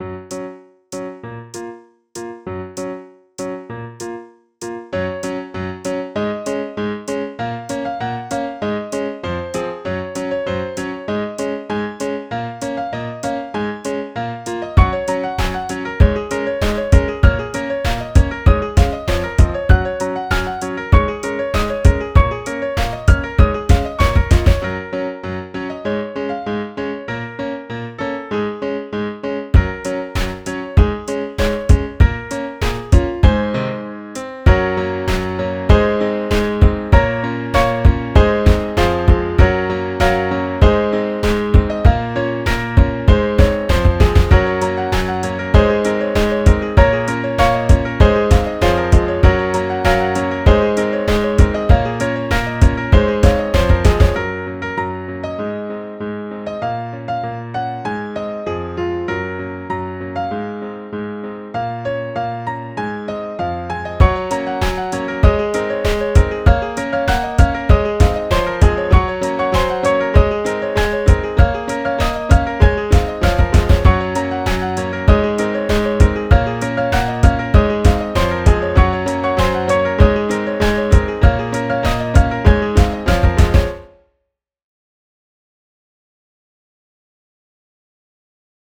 piano.ogg